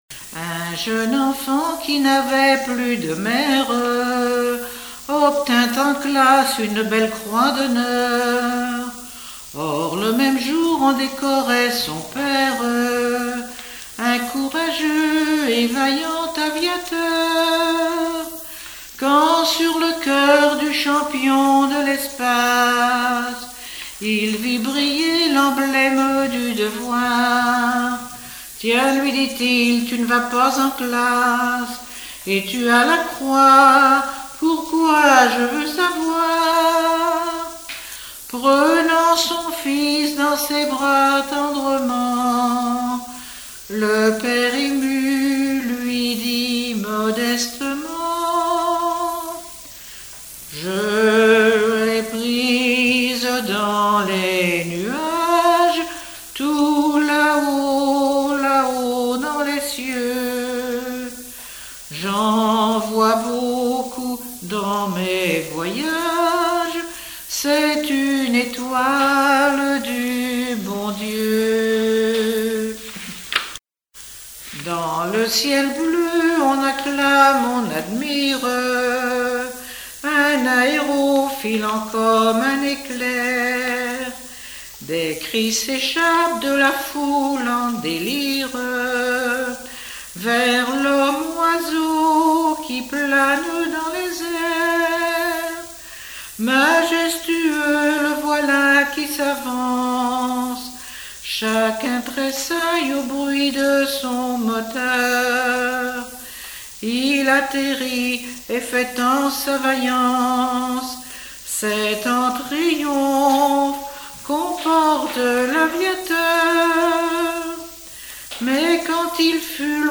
Mémoires et Patrimoines vivants - RaddO est une base de données d'archives iconographiques et sonores.
Genre strophique
Chansons de variété
Pièce musicale inédite